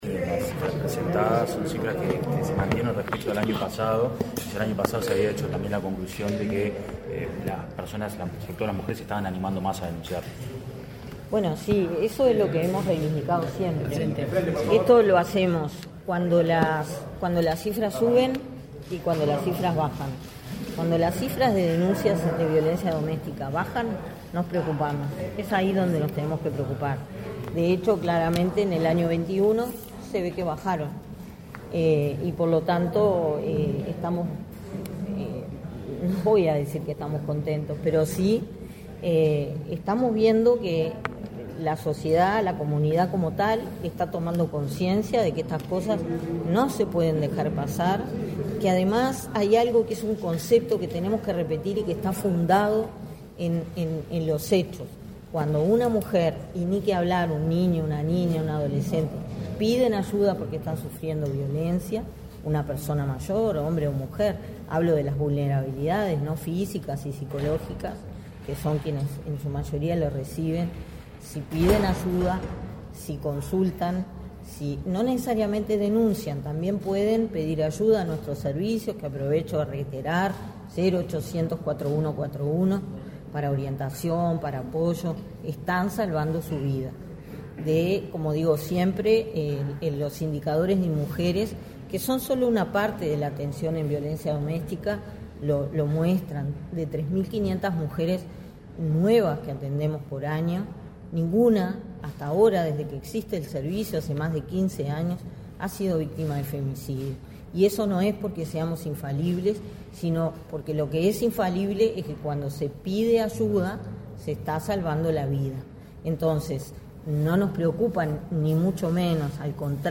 Declaraciones a la prensa de la directora de Inmujeres, Mónica Bottero
Tras participar en la presentación de los principales indicadores de violencia doméstica y de género, estudio elaborado por la Dirección Nacional de Políticas de Género respecto al primer semestre de 2024, este 7 de agosto, la directora del Instituto Nacional de las Mujeres (Inmujeres), Mónica Bottero, realizó declaraciones a la prensa.